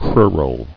[cru·ral]